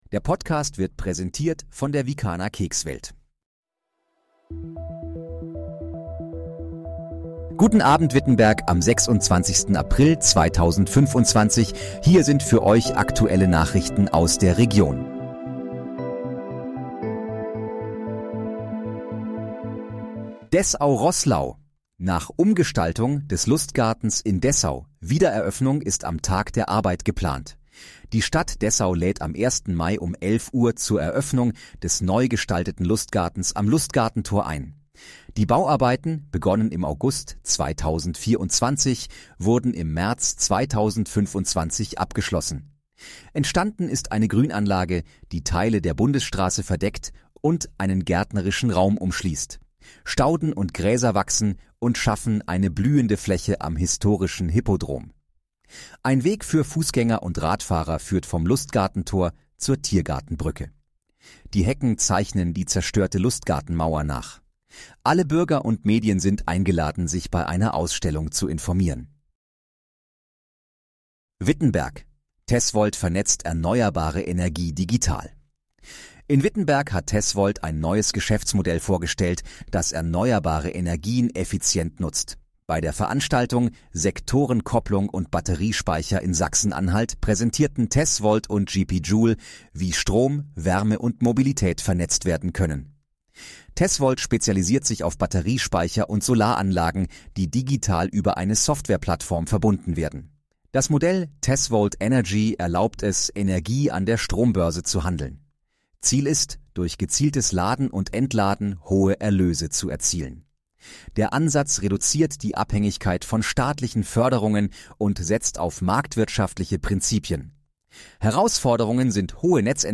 Guten Abend, Wittenberg: Aktuelle Nachrichten vom 26.04.2025, erstellt mit KI-Unterstützung
Nachrichten